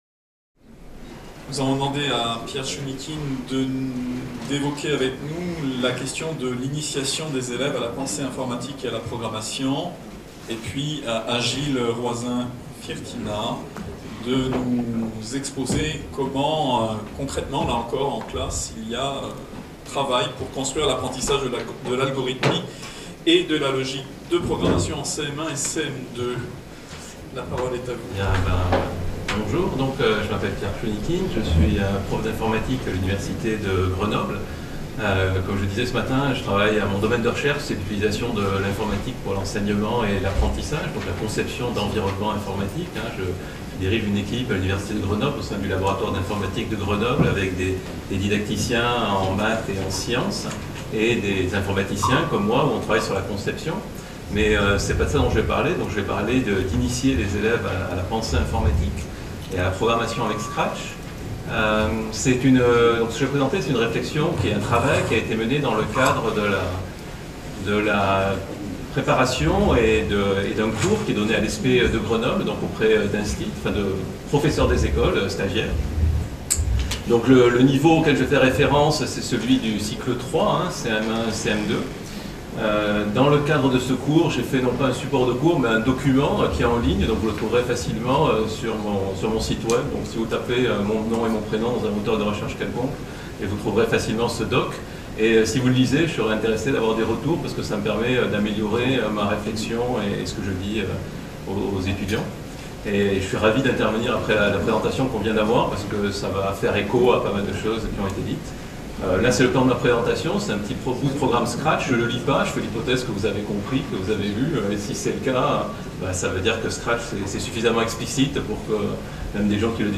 Table ronde des responsables des UNT: les ressources numériques éducatives libres (RNEL), levier de transformation des apprentissages dans l’enseignement supérieur